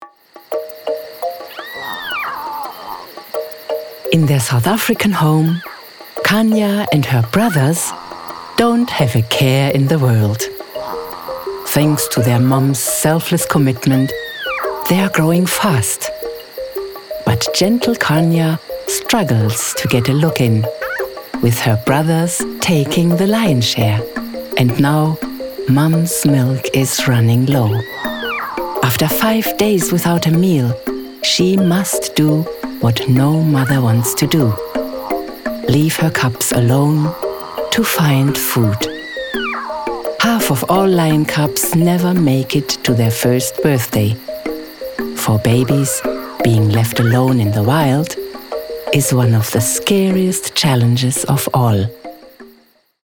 South Africa
caring, compassionate, maternal, nurturing
45 - Above
My demo reels